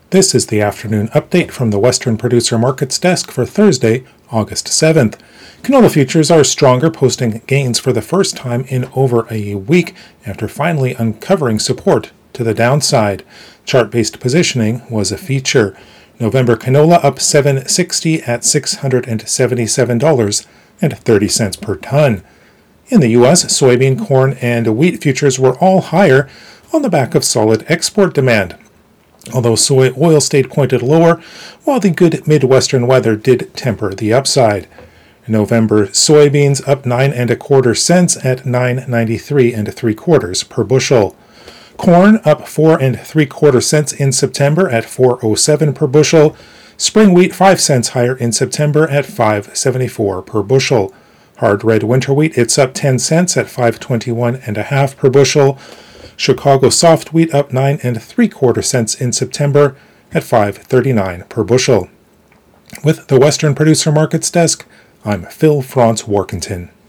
MarketsFarm's radio show is delivered twice per day - at noon and at the close of markets - and contains the latest information on the price of canola, wheat, soybeans, corn and specialty crops.